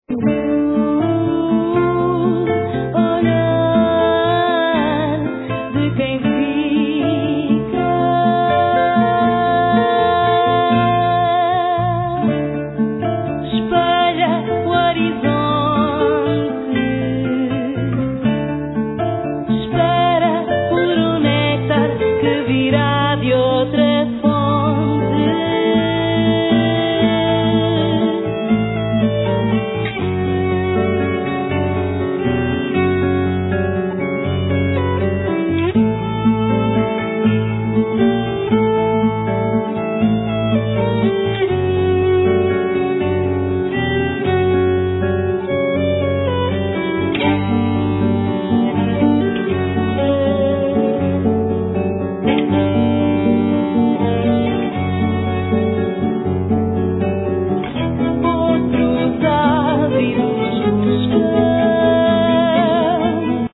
Vocals
Violin
Acoustic Guitar
Bass